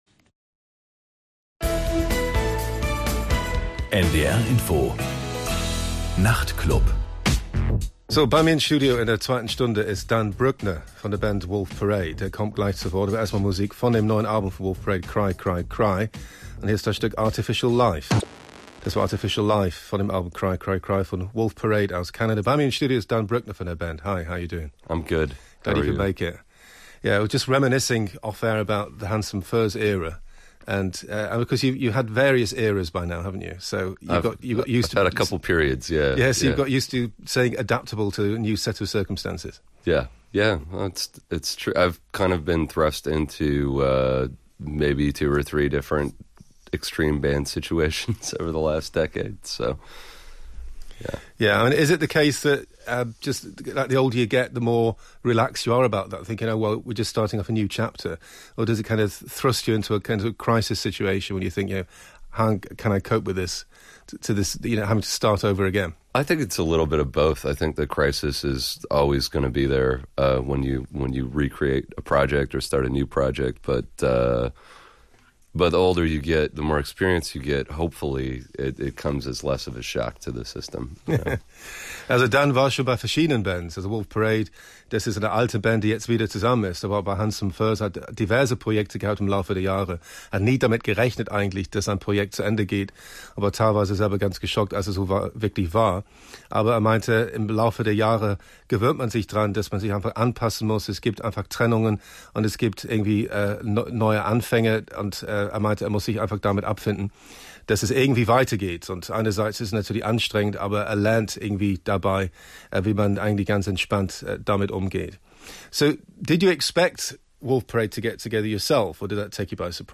Interview: Dan Boeckner (Wolf Parade) Typ: Aufzeichnung Sendung vom 07.01.2018 Länge: 31:30
– Aufzeichnung: Ähnlich wie die Live-Studio-Situation, nur eben tagsüber aufgezeichnet.